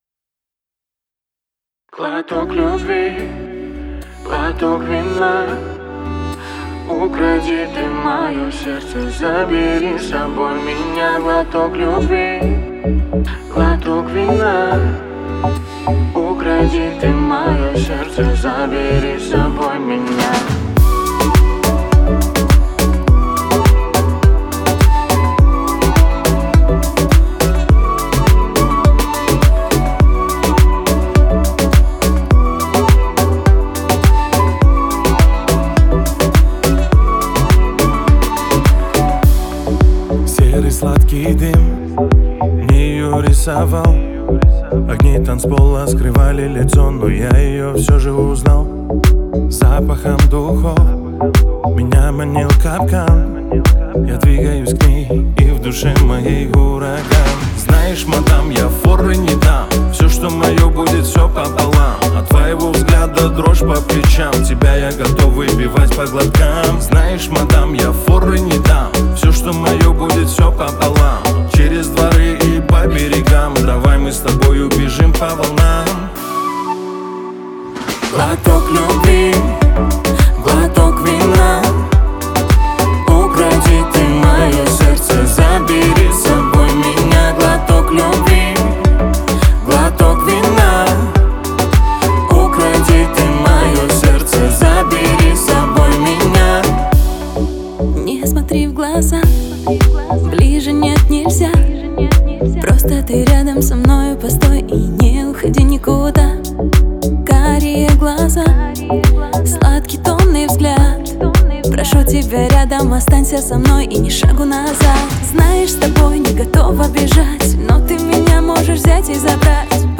это трек в жанре поп с элементами R&B, исполненный дуэтом